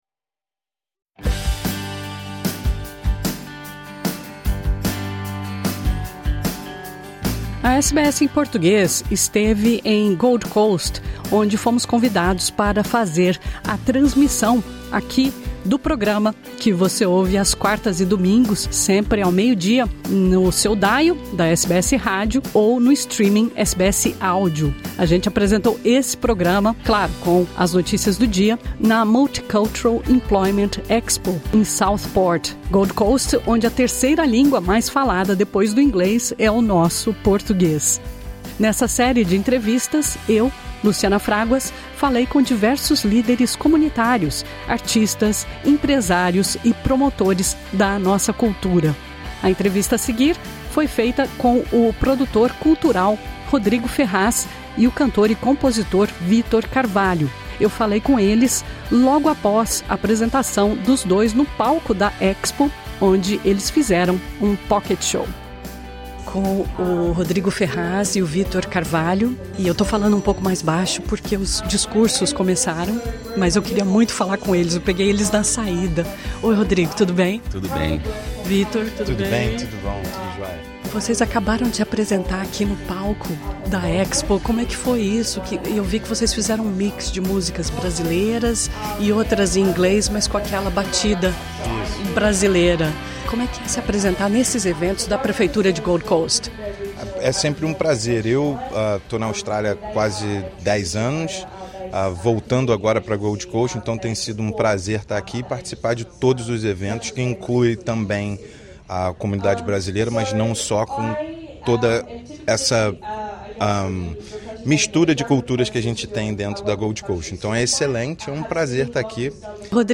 A SBS em Português encontrou os dois durante uma gig em Gold Coast.